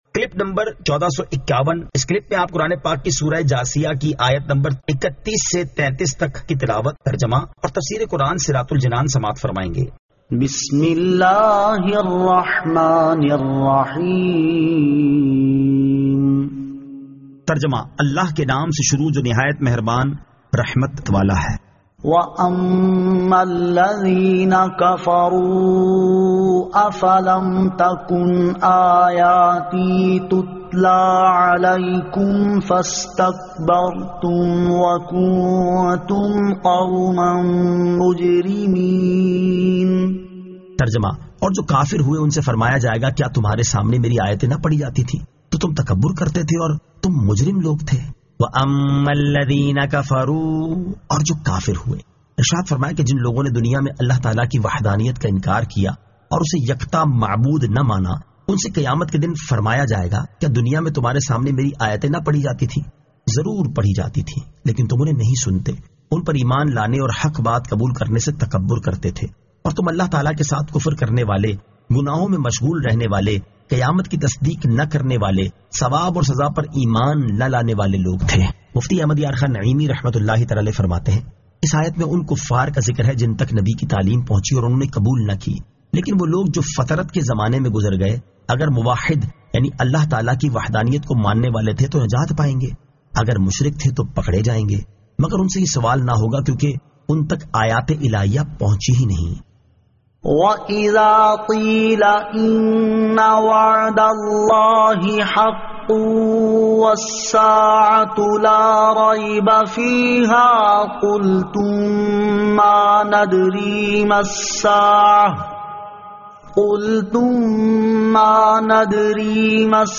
Surah Al-Jathiyah 31 To 33 Tilawat , Tarjama , Tafseer